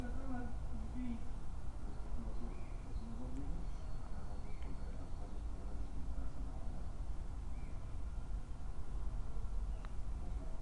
描述：学生生活的声音
声道立体声